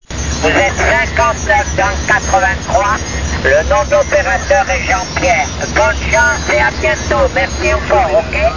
breve registrazione di cosa si ascolta con il ricevitore
(meta' mattina, puntale di tester come antenna)